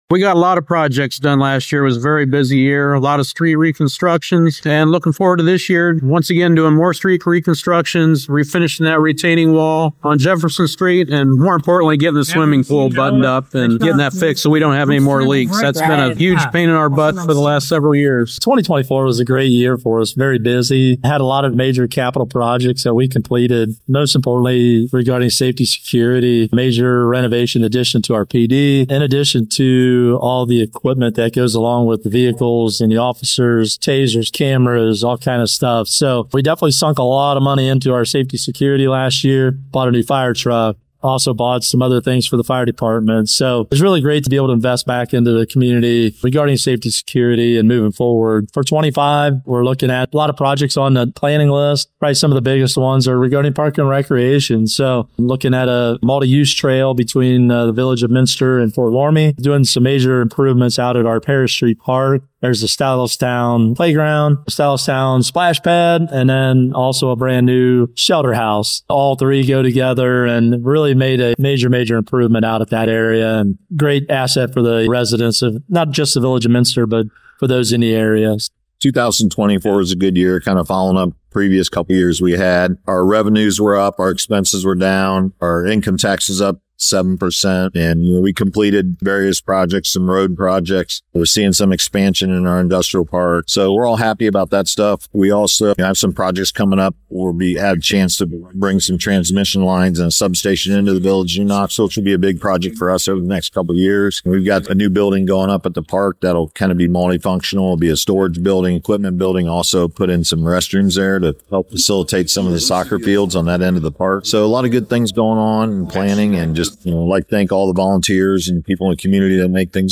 State of the Villages Breakfast held in New Knoxville
The Southwestern Auglaize County Chamber of Commerce held its annual State of the Villages Breakfast Wednesday morning in New Knoxville. Those in attendance heard from New Bremen Mayor Bob Parker, Minster Mayor Craig Oldiges and New Knoxville Mayor Keith Leffel. The Mayors reflected on 2024 and talked about their outlook for 2025.